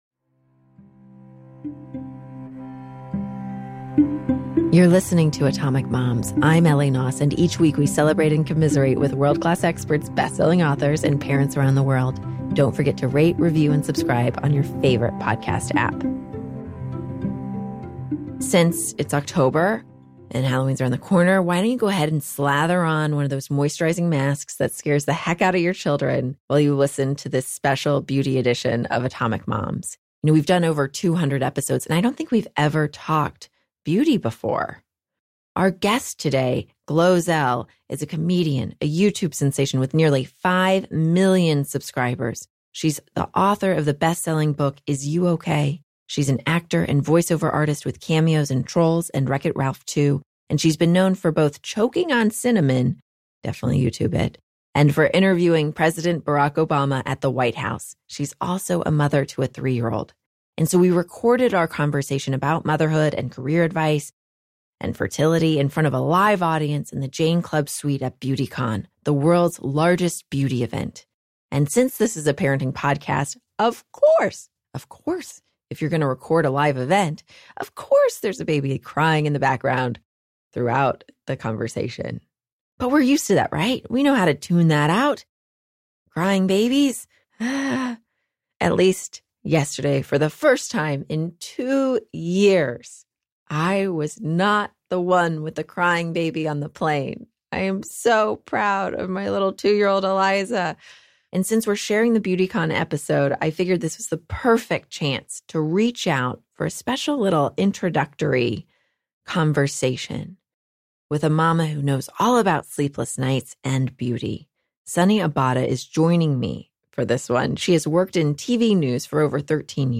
In this live episode in front of an audience of diehard GloZell fans, we discuss everything from screen-time (how does a Youtube star manage to get an iPad away from her toddler?) to the harsh realities of becoming a first-time mother at forty-four.